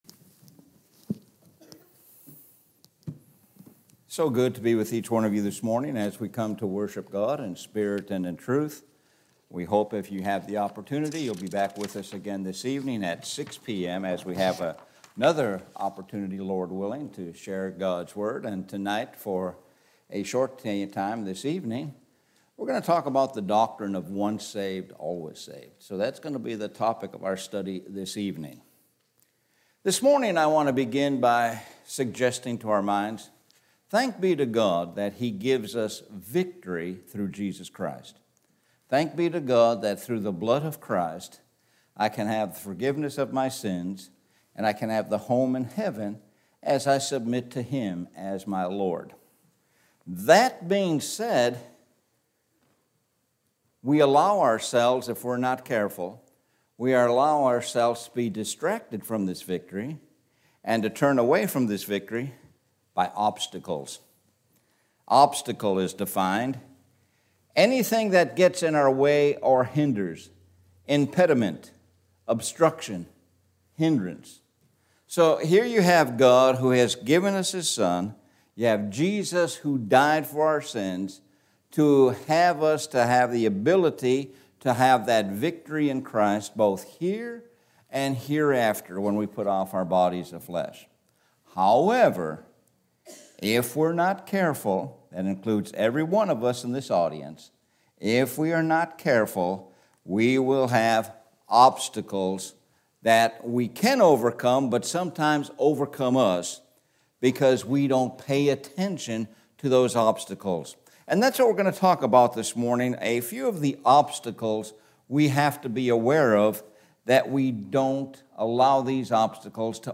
Sun Am Sermon – Obstacles to Overcome